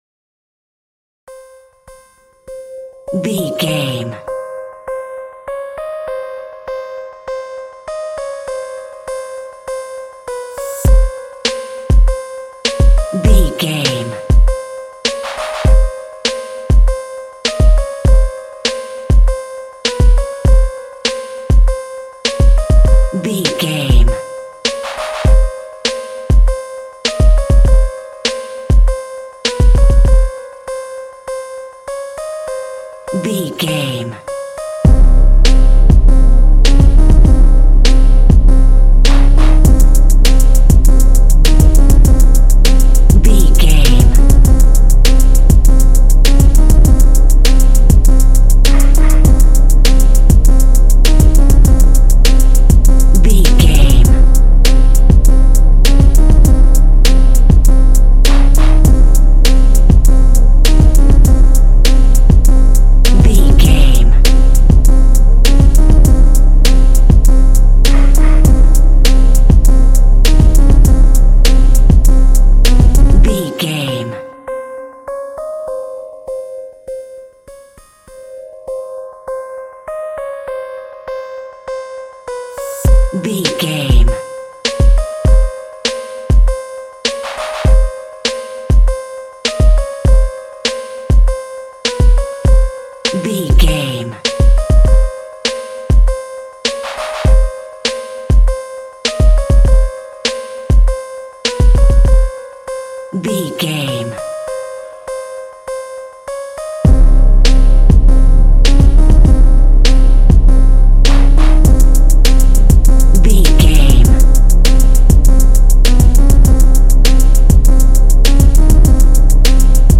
Phrygian
hip hop instrumentals
chilled
laid back
groove
hip hop drums
hip hop synths
piano
hip hop pads